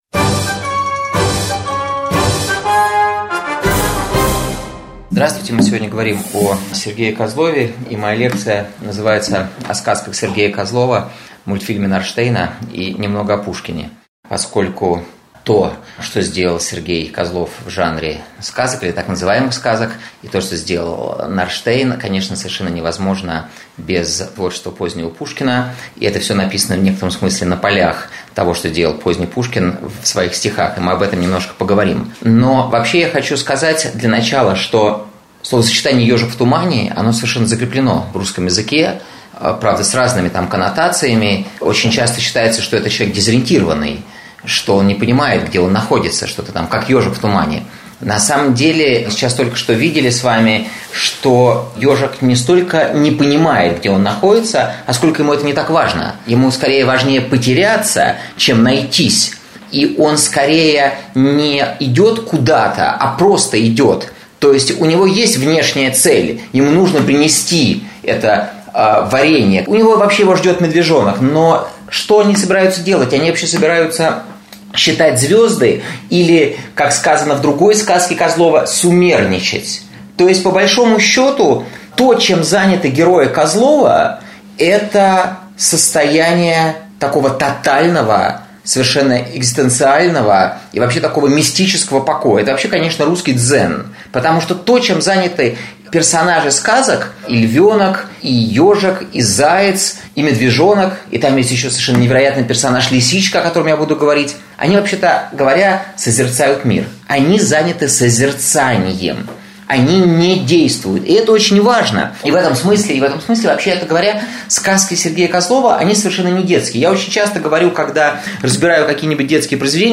Аудиокнига Сергей Козлов. Сказки. Как обрести гармонию и повзрослеть, гуляя в тумане рядом с Ежиком | Библиотека аудиокниг